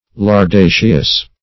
Search Result for " lardaceous" : The Collaborative International Dictionary of English v.0.48: Lardaceous \Lar*da"ceous\, a. [Cf. F. lardac['e].] Consisting of, or resembling, lard.